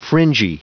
Prononciation du mot fringy en anglais (fichier audio)
Prononciation du mot : fringy